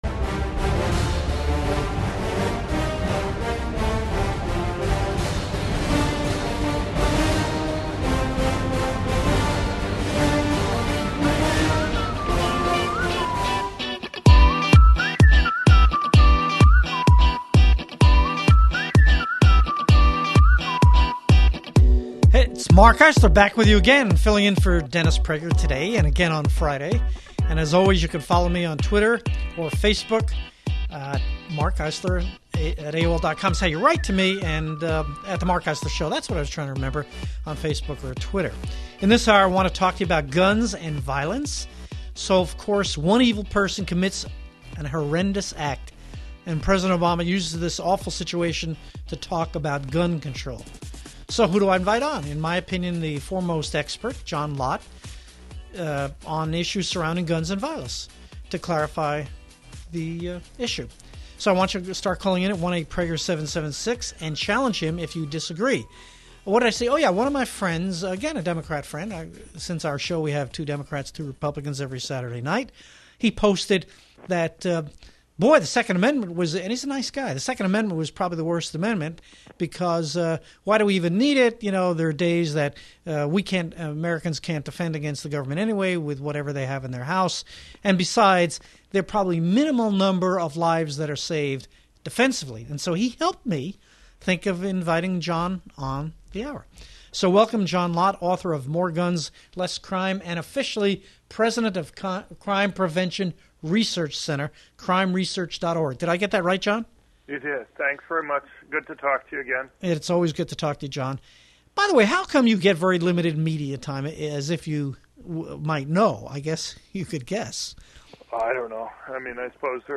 CPRC on the Dennis Prager National Radio Show: Discussing the Aftermath of Charleston